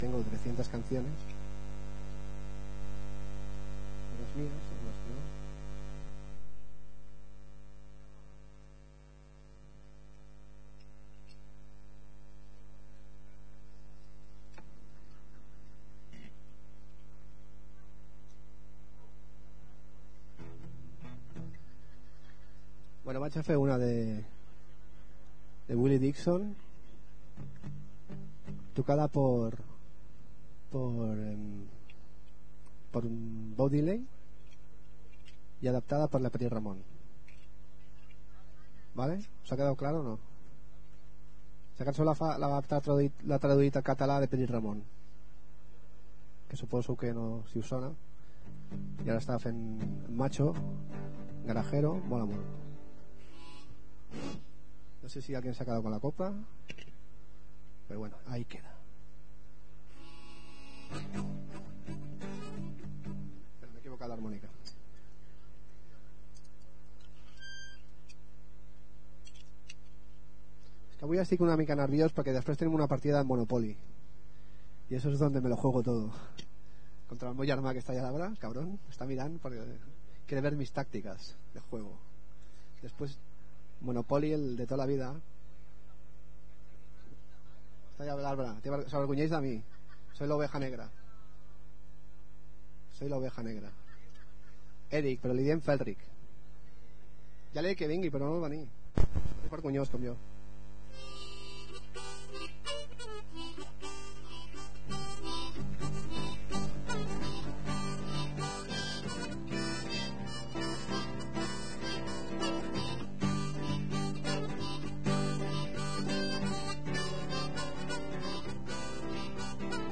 Grabació realitzada conjuntament amb Ràdio Bronka durant la prerevetlla del passat 23 de juny a la Plaça de la Farigola amb les actuacions i xerrada veïnal realitzada